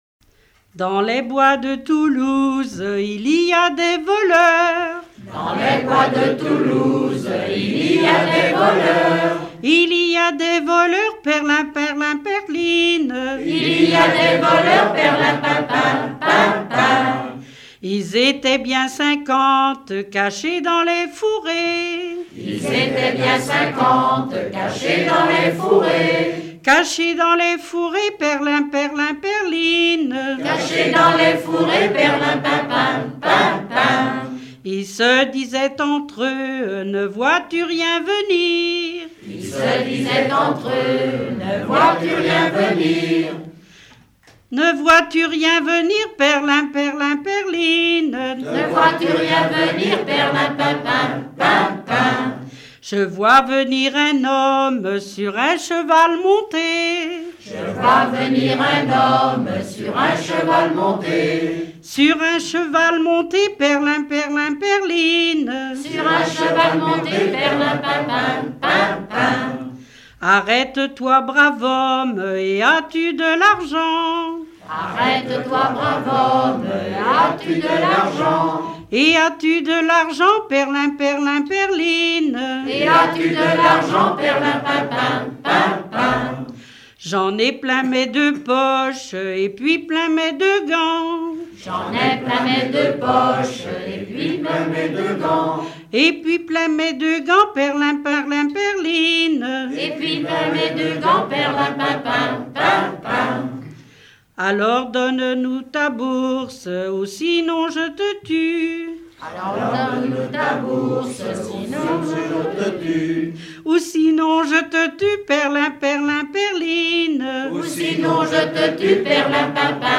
Genre laisse
Regroupement de chanteurs du canton
Pièce musicale inédite